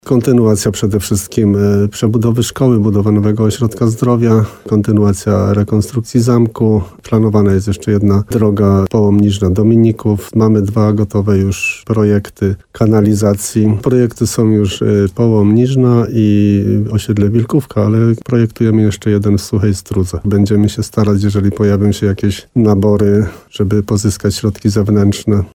Jak przyznał w programie Słowo za Słowo w radiu RDN Nowy Sącz wójt gminy Rytro Jan Kotarba, to będzie połączenie nowych inwestycji z tymi, które już są kontynuowane.